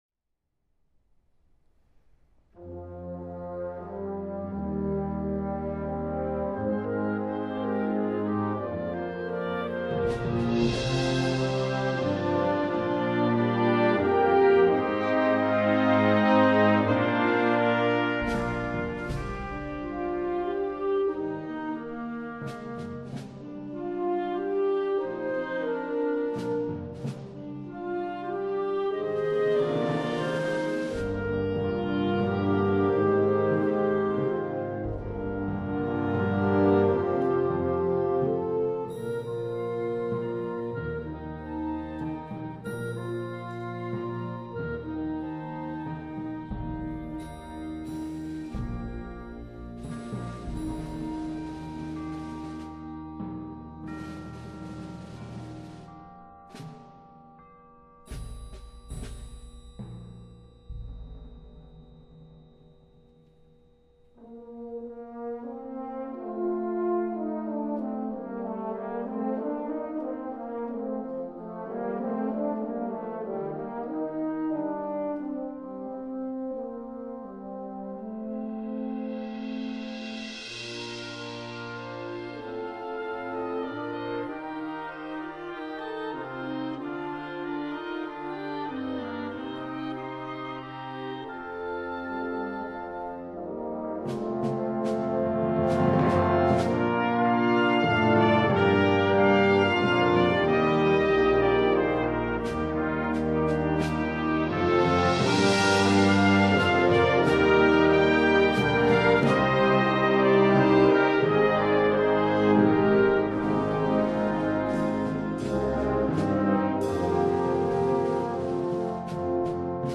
Genre: Band
Instrumentation
Timpani
Percussion 2 (Snare Drum)